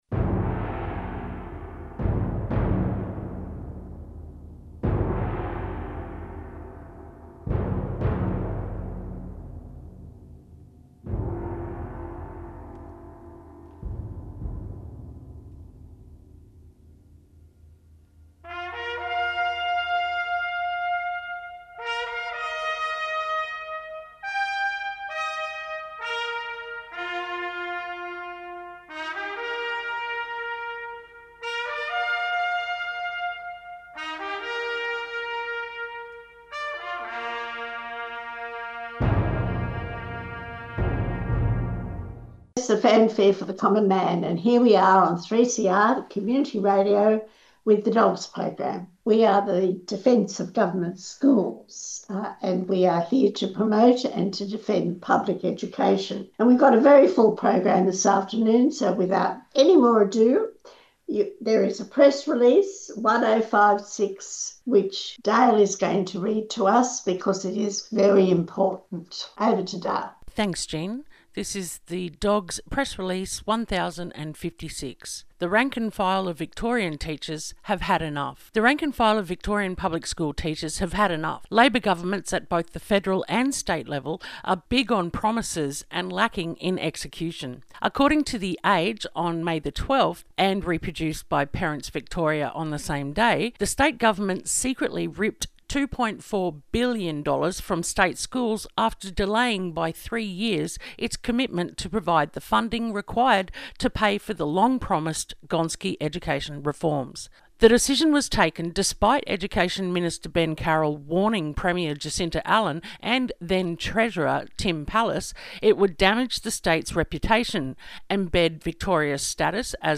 Empassioned speech from Tasmanian Teacher at a recent rally. A Melbourne school hired a chef and it was an instant hit with staff and students.